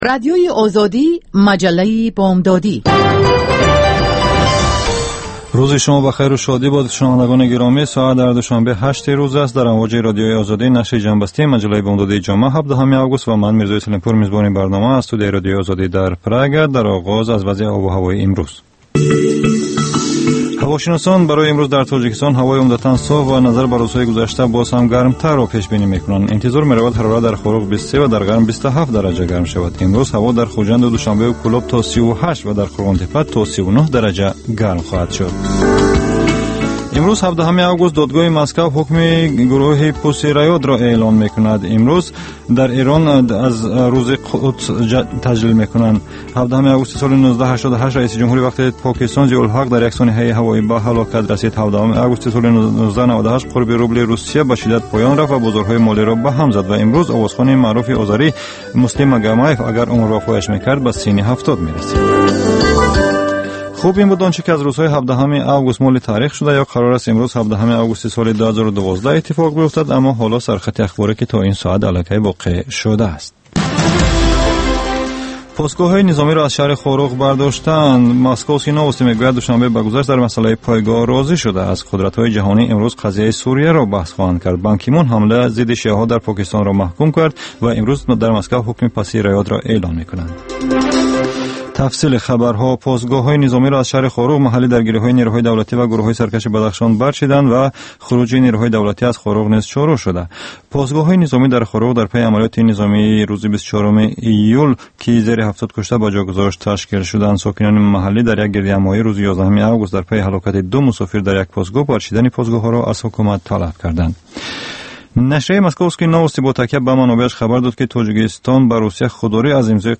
Фишурдаи охирин ахбори ҷаҳон, гузоришҳо аз Тоҷикистон, гуфтугӯ ва таҳлилҳо дар барномаи бомдодии Радиои Озодӣ.